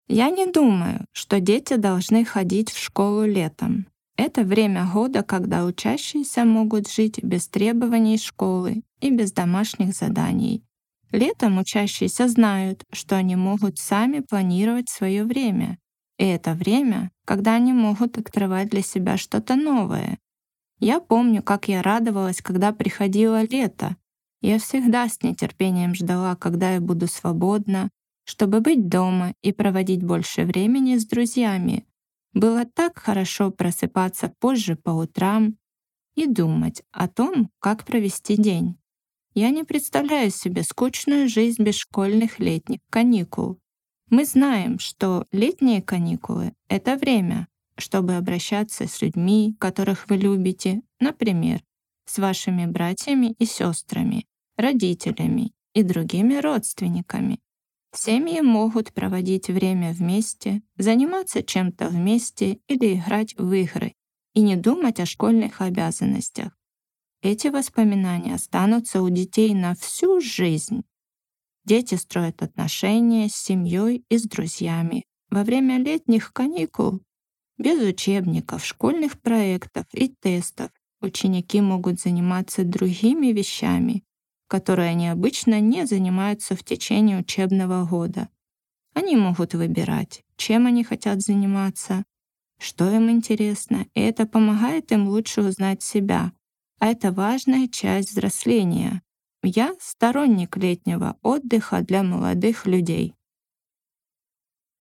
[Note: In the transcript below, ellipses indicate that the speaker paused.]